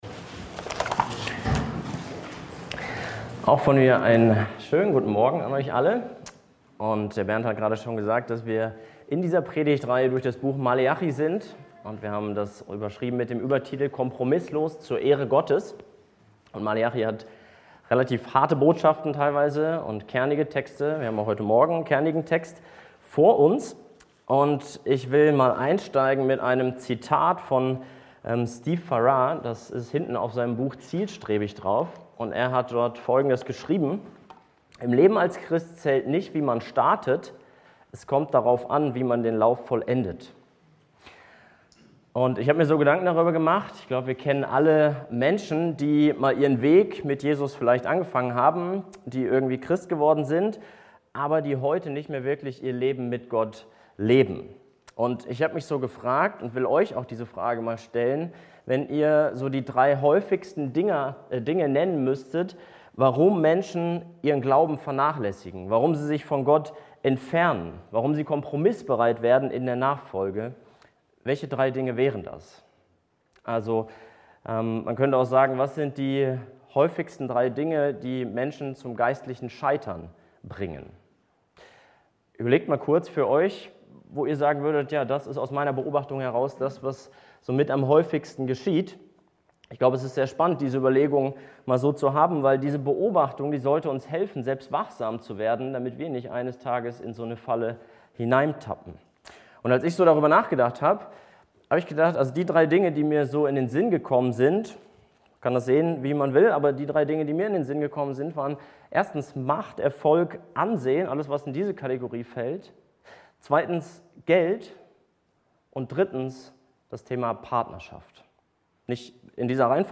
Thematik Lehre